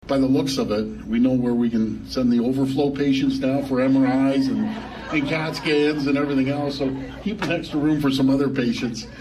The Premier made that joke at a new veterinary facility north of Toronto.